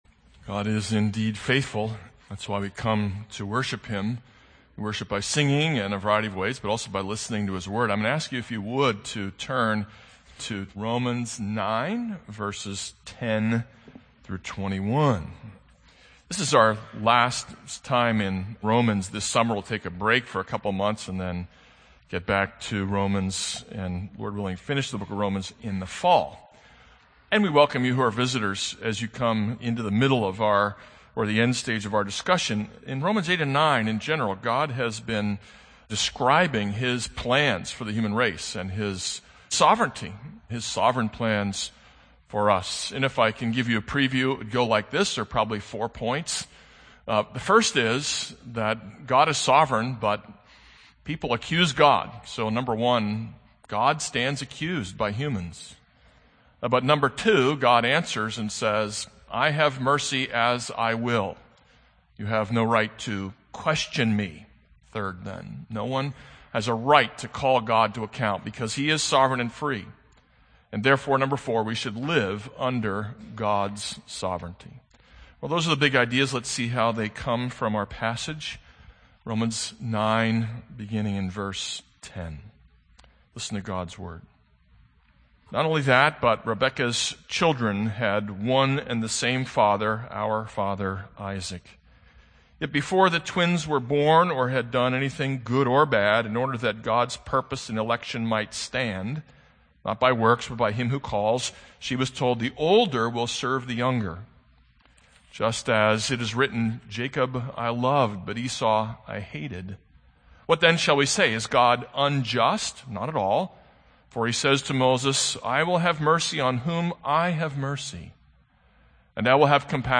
This is a sermon on Romans 9:14-24.